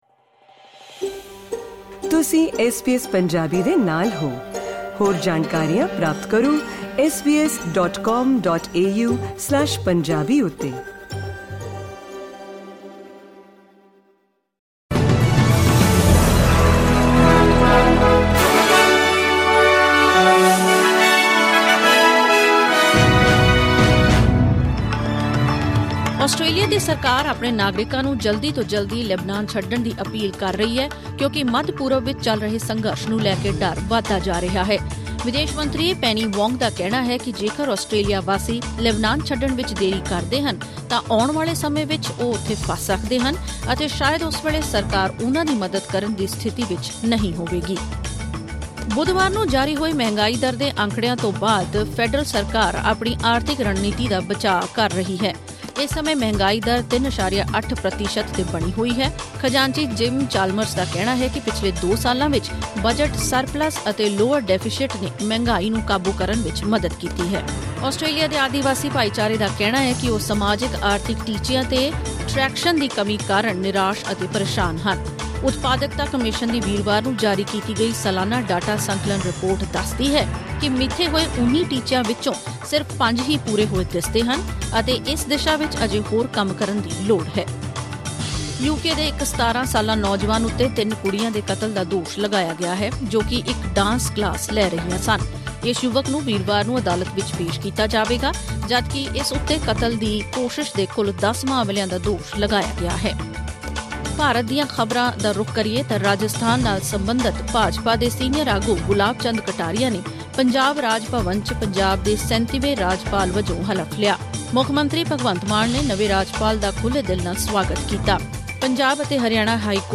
ਐਸ ਬੀ ਐਸ ਪੰਜਾਬੀ ਤੋਂ ਆਸਟ੍ਰੇਲੀਆ ਦੀਆਂ ਮੁੱਖ ਖ਼ਬਰਾਂ: 1 ਅਗਸਤ 2024